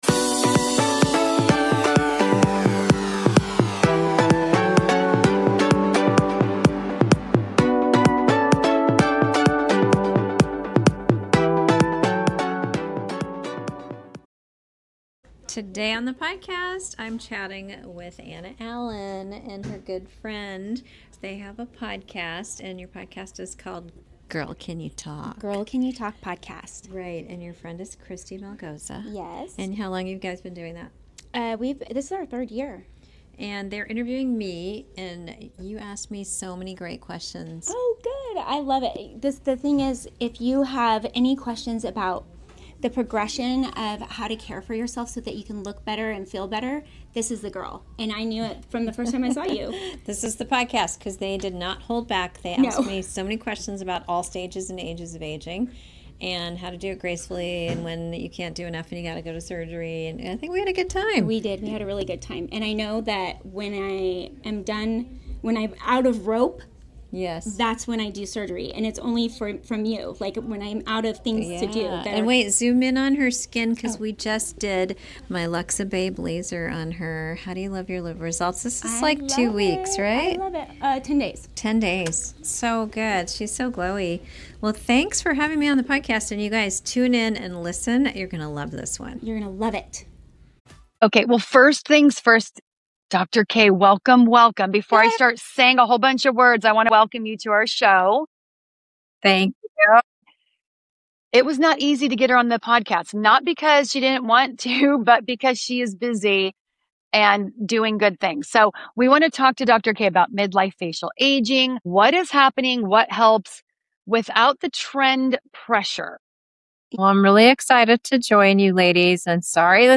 If you want the true roadmap to midlife rejuvenation without looking “overdone,” you cannot miss this conversation.